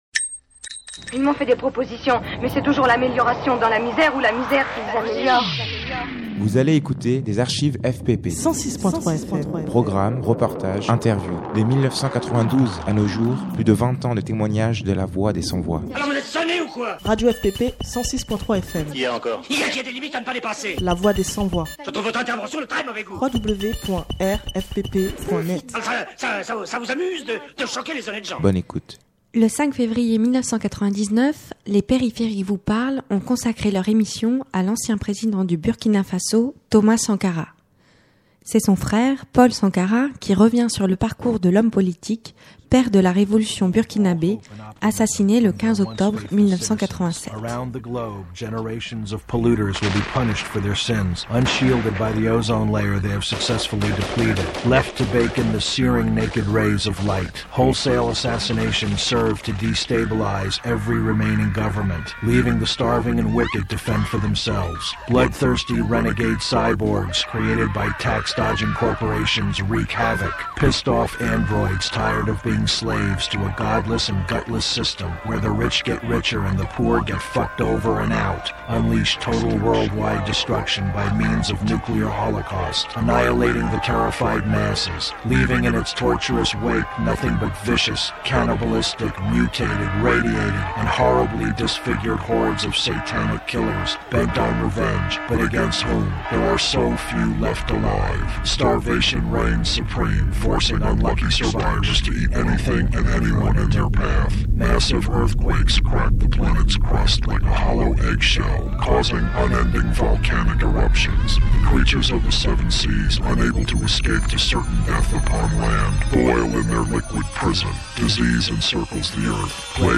L’archive numérisée a été diffusée à l’antenne, en deux parties, les 11 et 18 février 2016, entre 16h et 17h.